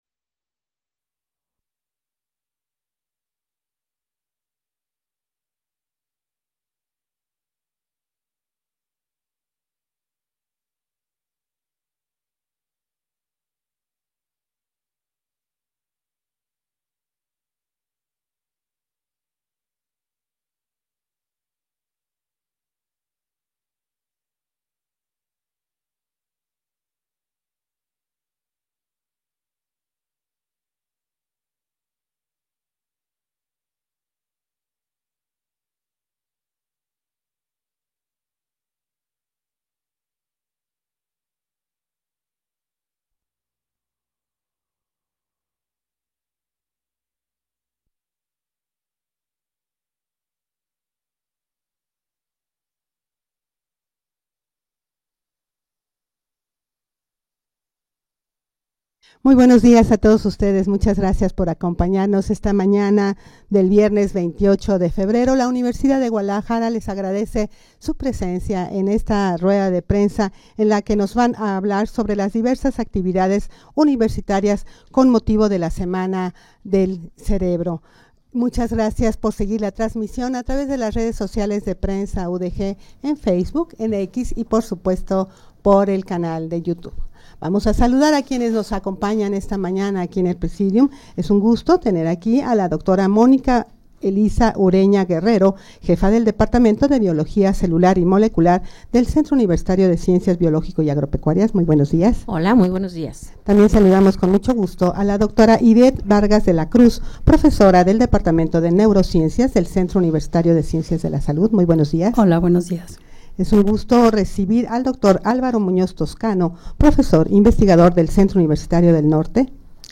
Audio de la Rueda de Prensa
rueda-de-prensa-para-dar-a-conocer-las-actividades-universitarias-con-motivo-de-la-semana-del-cerebro.mp3